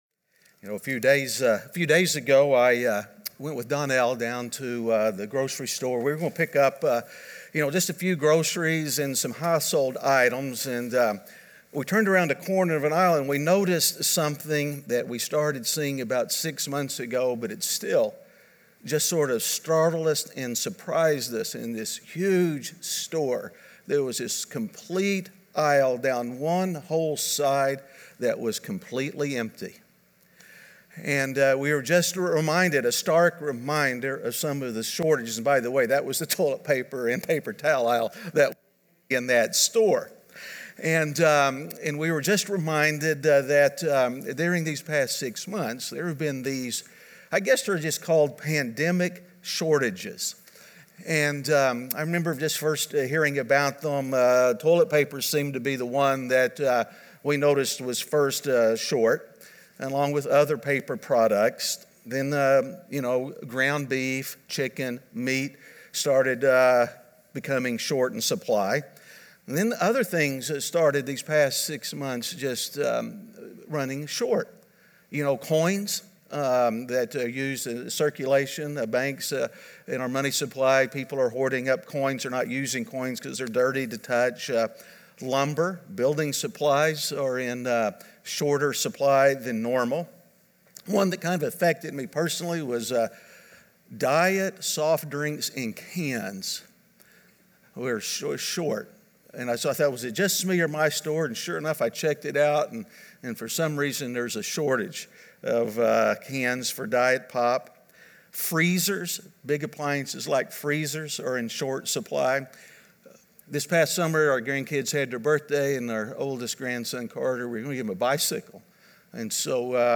Standing Firm In Shaky Times (Week 6) - Sermon.mp3